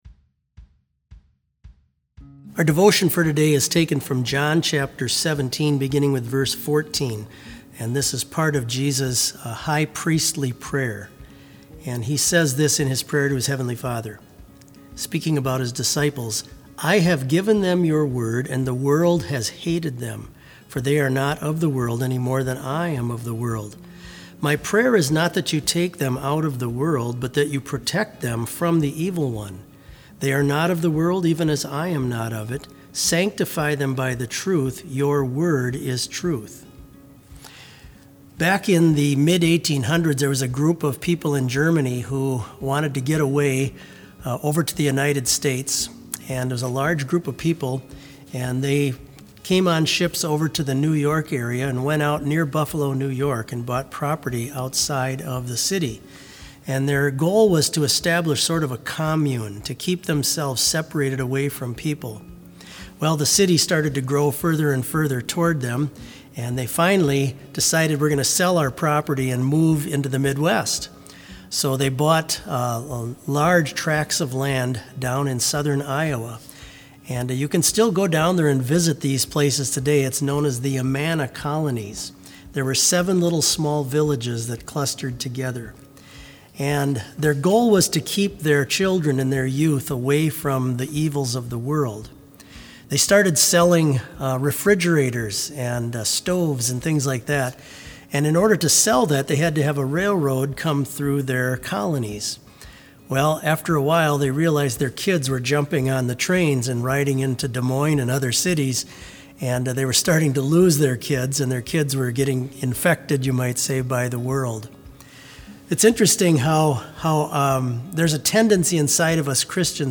Complete service audio for BLC Devotion - May 4, 2020